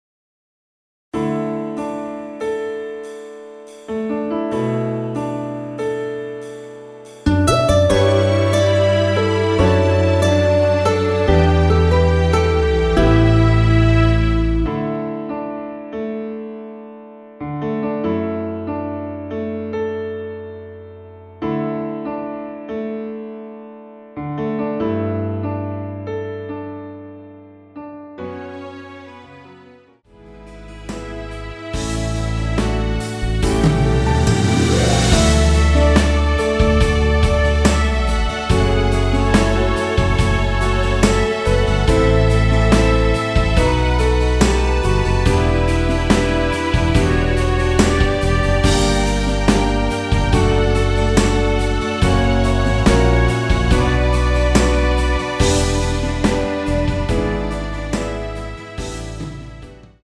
원키 MR입니다.
D
앞부분30초, 뒷부분30초씩 편집해서 올려 드리고 있습니다.
중간에 음이 끈어지고 다시 나오는 이유는